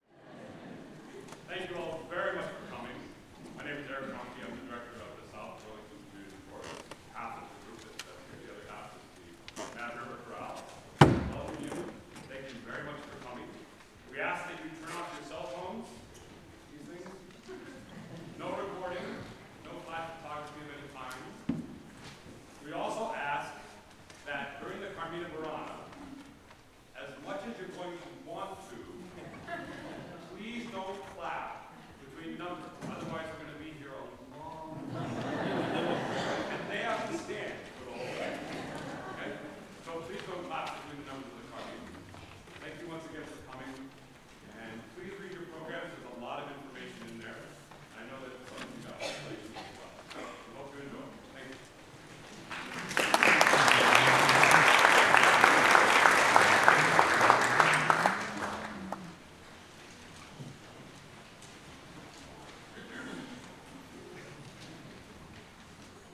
our May 4th concert